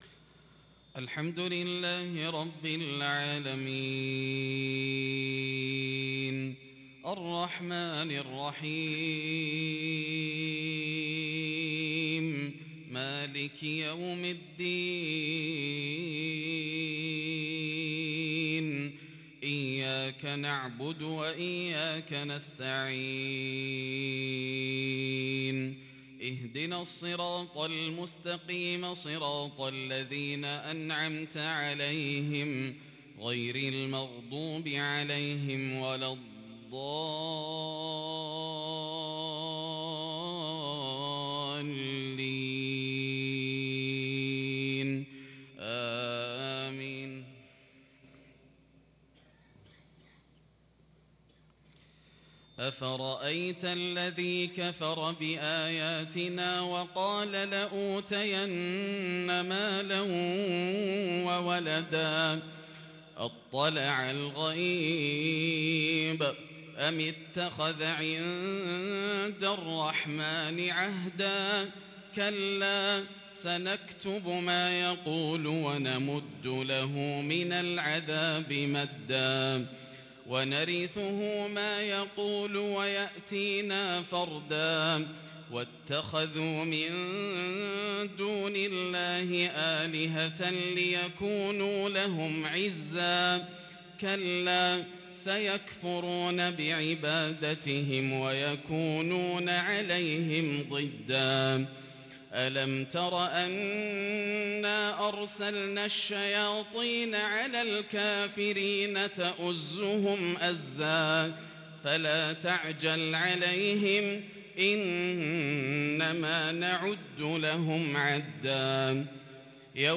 ترتيل خاشع بديع لآواخر سورتي مريم و النبأ - عشاء الثلاثاء 2-4-1442 > عام 1442 > الفروض - تلاوات ياسر الدوسري